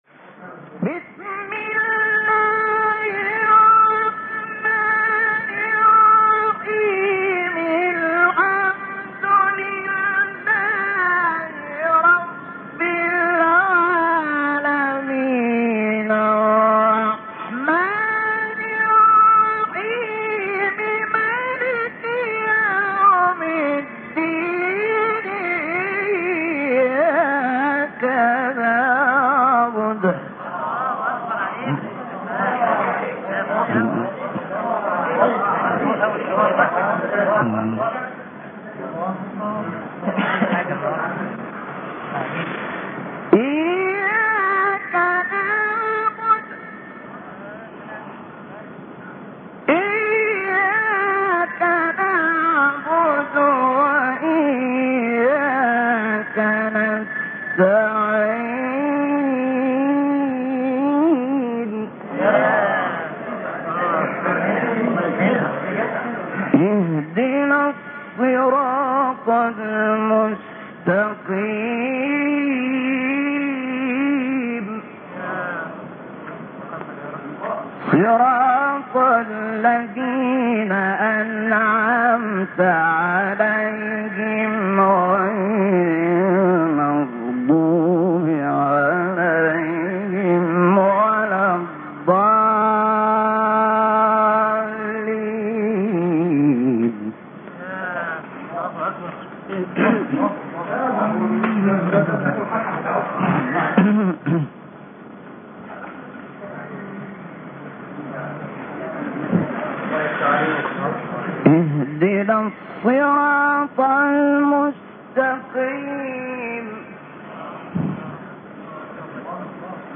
تلاوت زیبای سوره حمد توسط استاد شحات محمد انور | نغمات قرآن | دانلود تلاوت قرآن